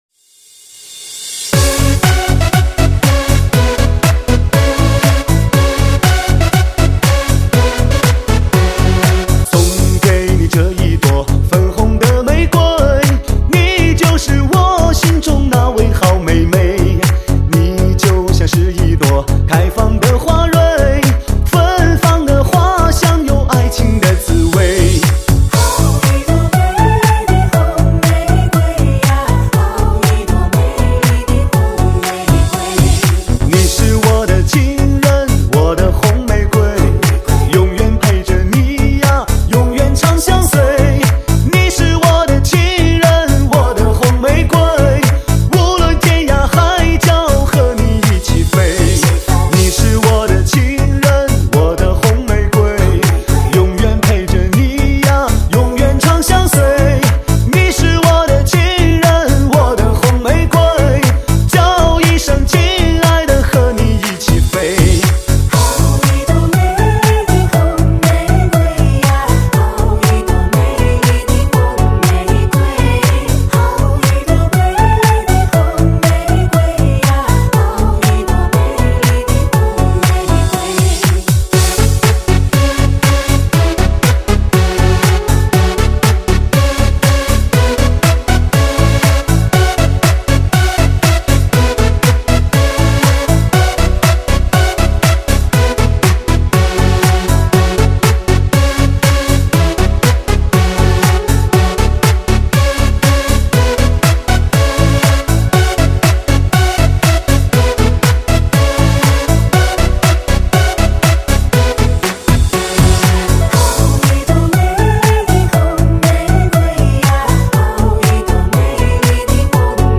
中文的士高 THE BEST DISCO
至尊震憾流行金曲电音大碟 享爱独一无二的潮流音乐快感
经典舞曲大碟，节奏轻松欢快